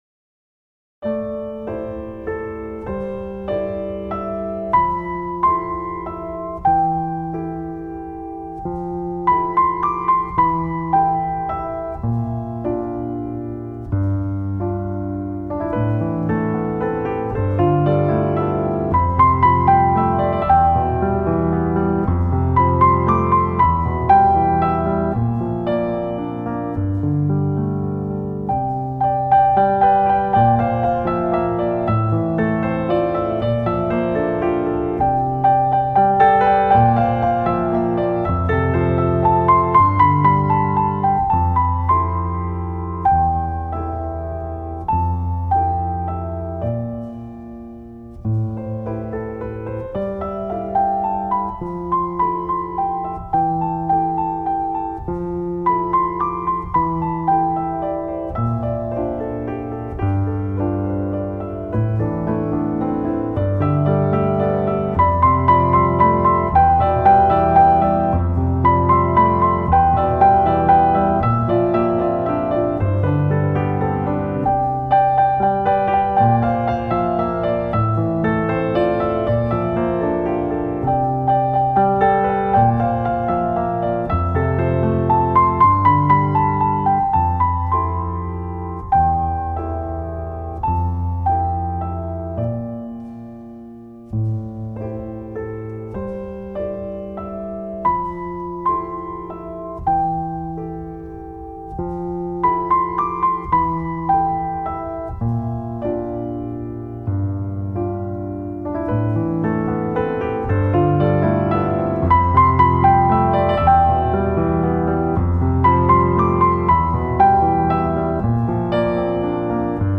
Piano Solo
最后当音乐终止在乐句中段
很典型的新世纪音乐